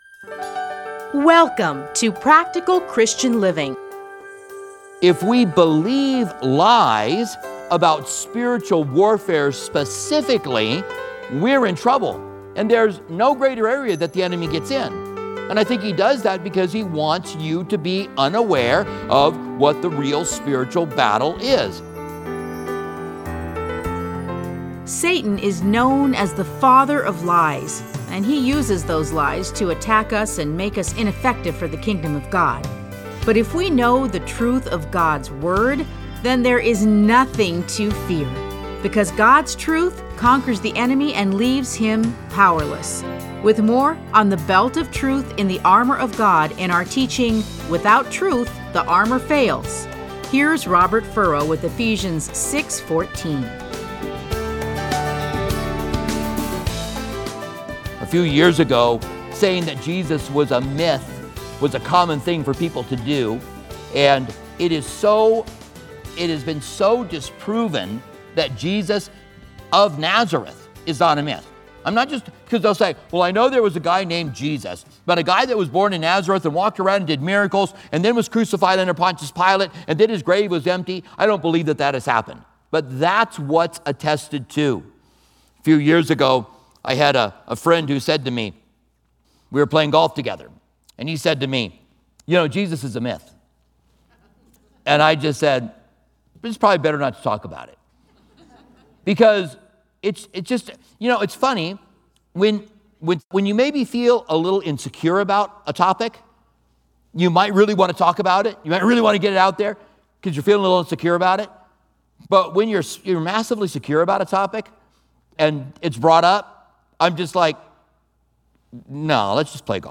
Listen to a teaching from Ephesians 6:14.